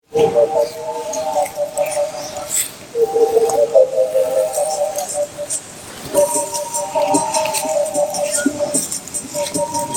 gefilterte Version, Eislaufgeräusche sind vermindert, dafür ist die Melodie etwas digital.